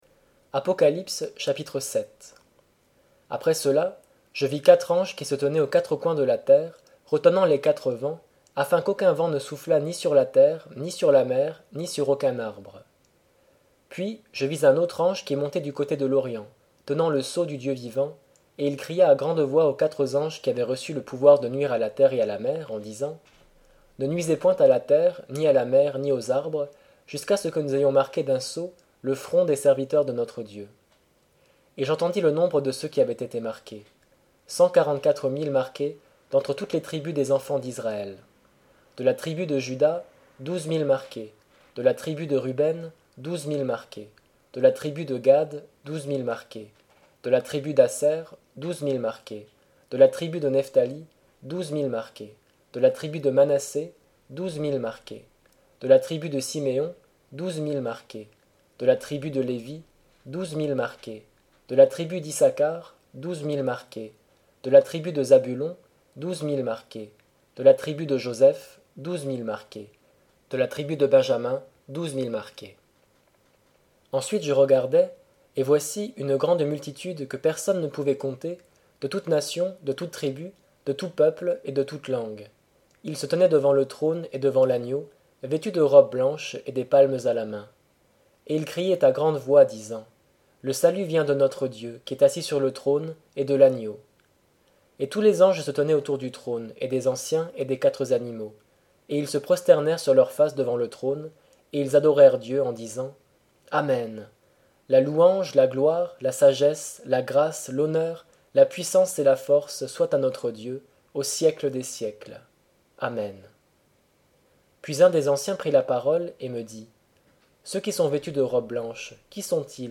Livre audio Apocalypse